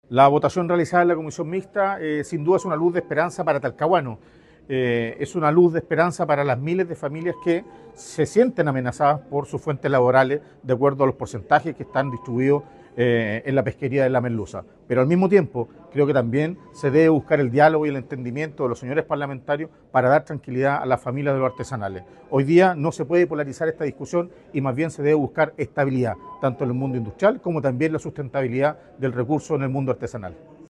También estuvo presente en la comisión el alcalde de Talcahuano, Eduardo Saavedra, quien declaró que la votación “es una luz de esperanza para quienes ven amenazadas sus fuentes laborales”.